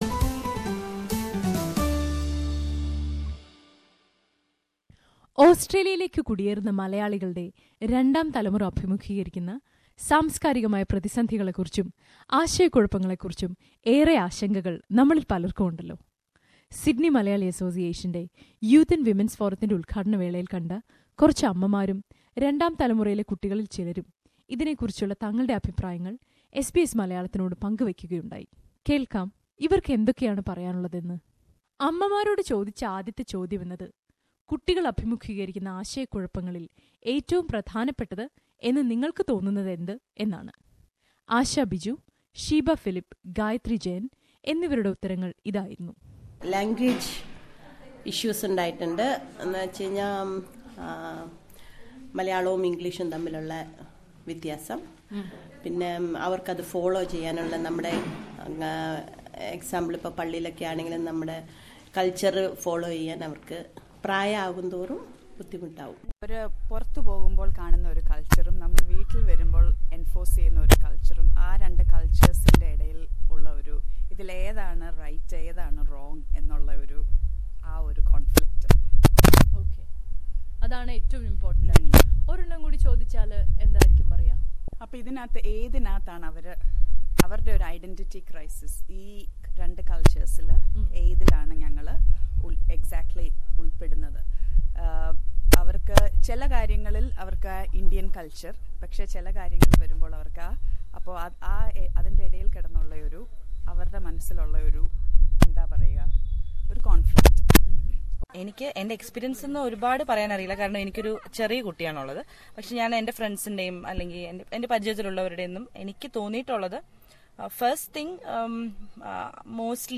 Migrant parents who settle here often find it hard to decide on what is best for their children in the diverse multicultural environment their children live in. In the first part of the series about migrant life in Australia, parents and children comment on some of the most common migrant issues around growing up in a multicultural atmosphere in Australia.